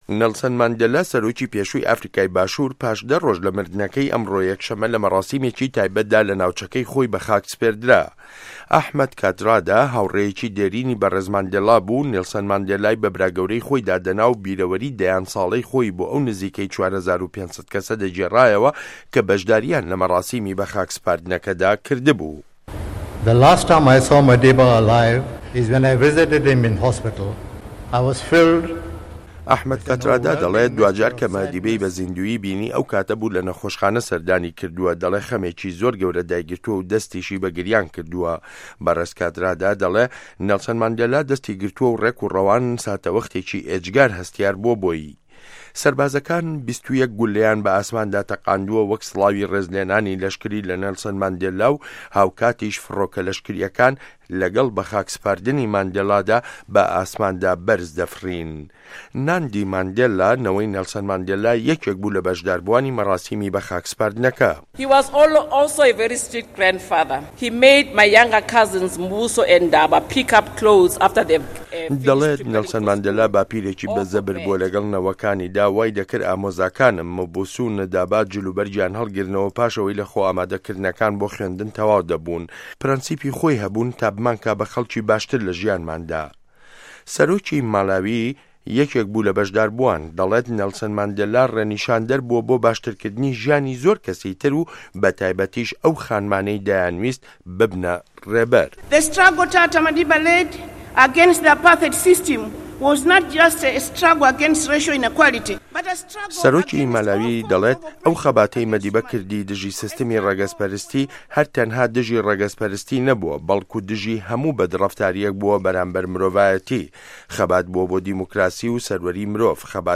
وتاری هاوڕێ و که‌سوکاری نێڵسۆن ماندێلا له‌ مه‌راسیمی به‌خاکسـپاردنه‌که‌یدا
ڕاپـۆرتی ماندێلا